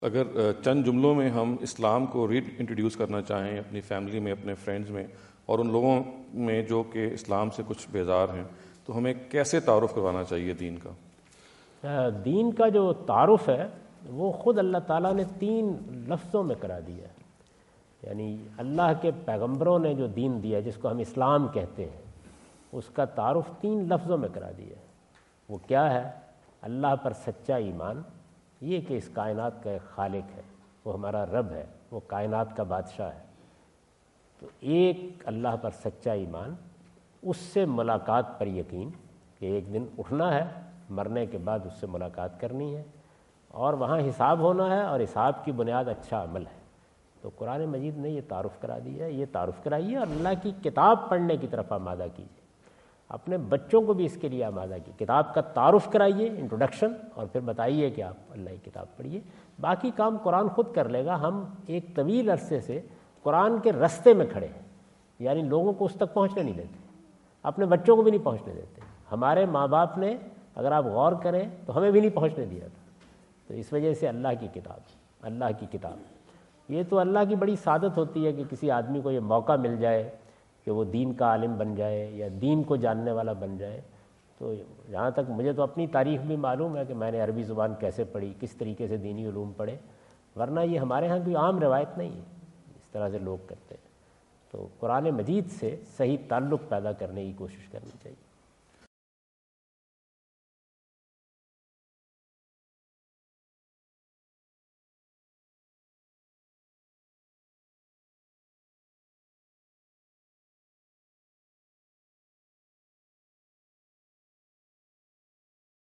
Category: English Subtitled / Questions_Answers /
In this video Javed Ahmad Ghamidi answer the question about "how to reintroduce family members to Islam?" asked at The University of Houston, Houston Texas on November 05,2017.